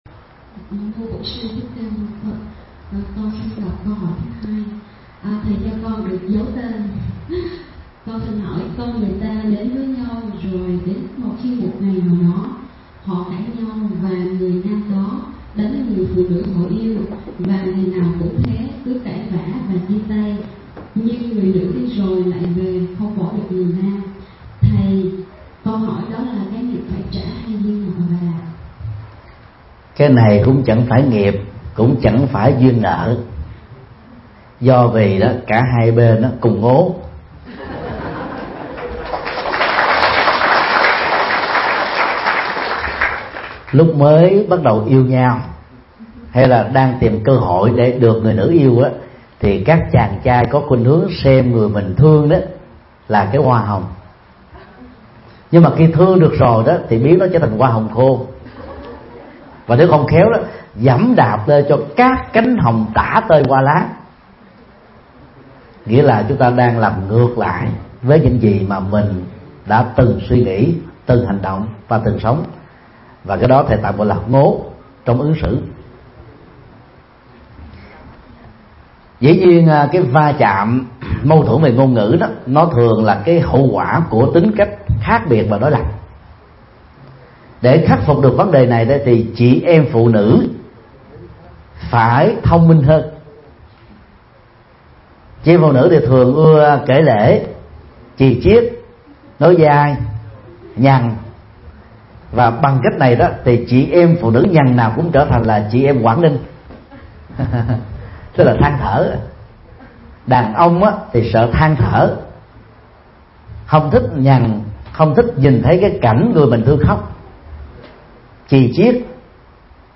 Nghe mp3 Vấn đáp: Ứng xử tình huống yêu người không thương mình – Thầy Thích Nhật Từ